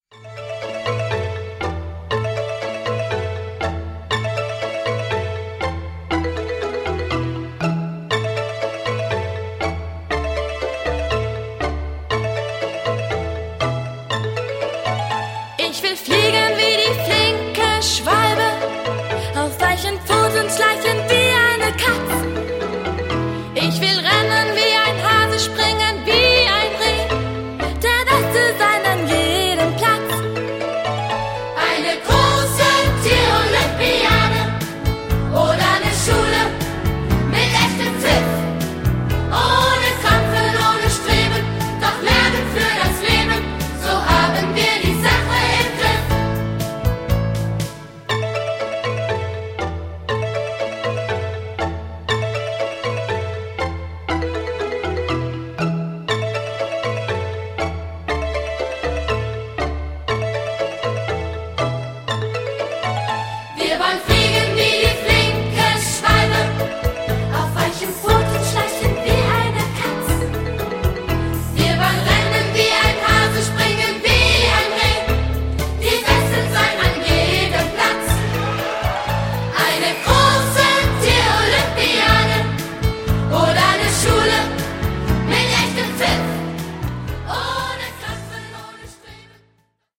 Ein Musical für Kinder